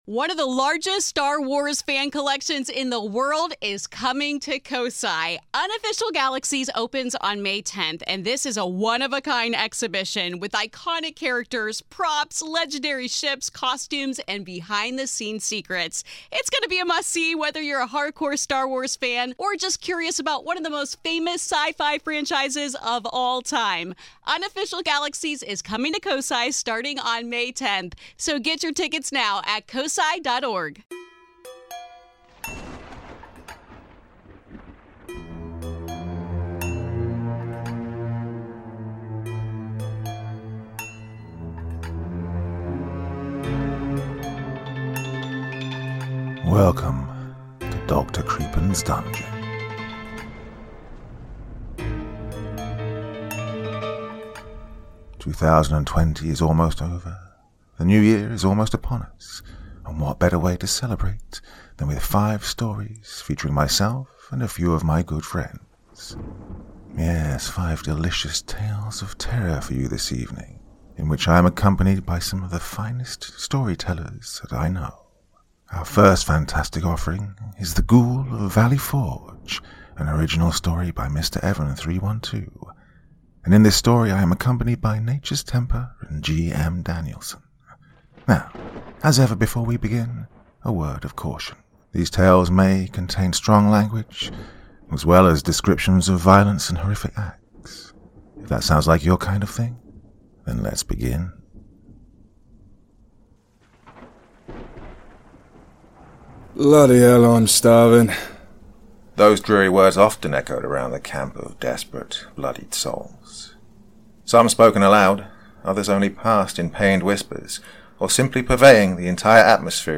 Join me for the final podcast of 2020 with 5 terrifying tales of horror and suspense!